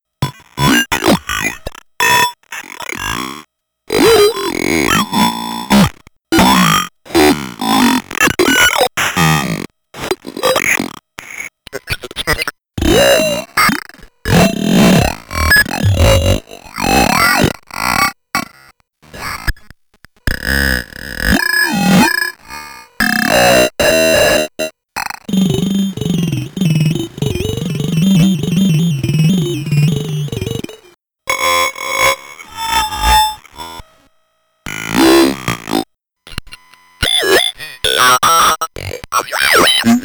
Alien conversation 1
alienvoicecollection1.mp3